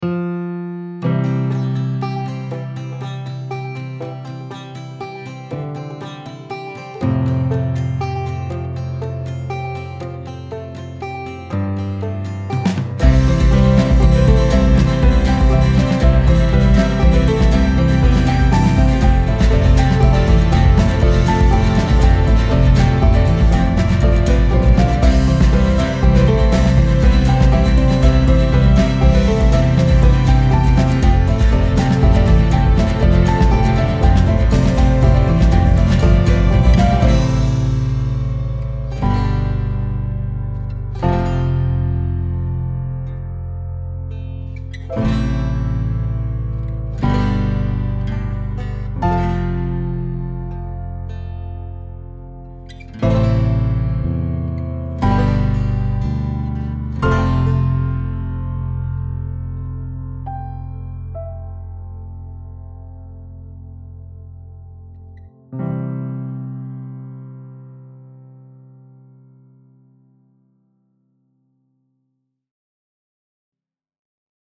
is a pop song with energizing vibes
With gleeful acoustic guitar harmonies
110 BPM
pop energizing happy uplifting acoustic guitar piano drums